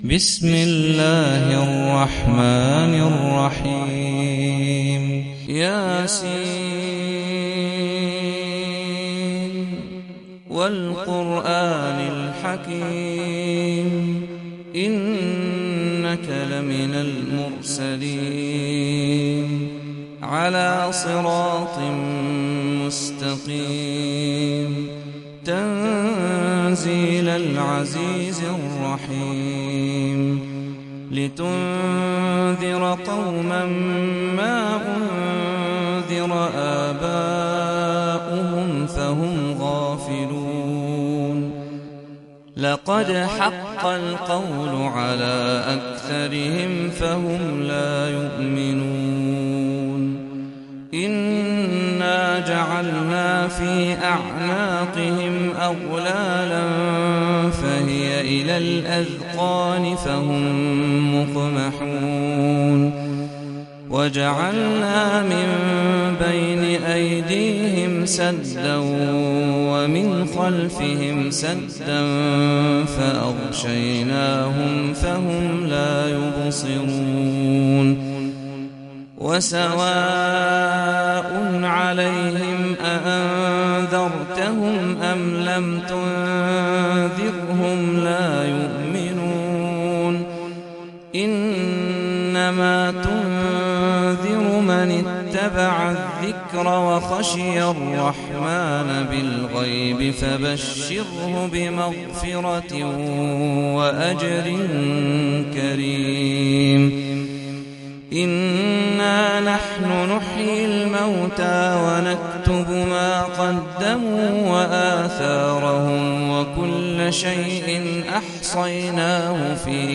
Sûrat Ya-seen - صلاة التراويح 1446 هـ (Narrated by Hafs from 'Aasem)